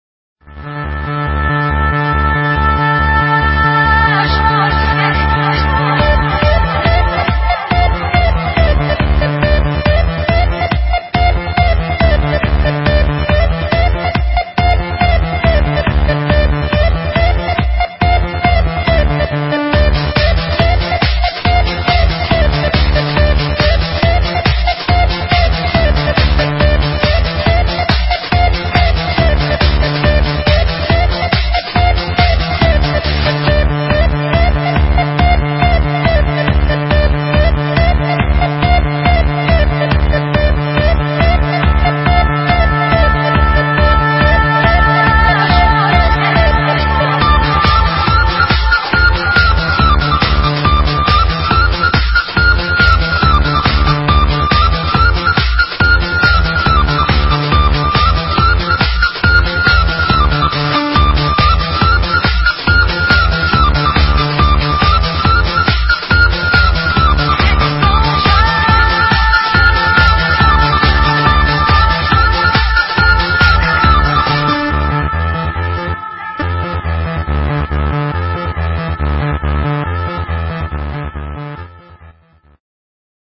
DNB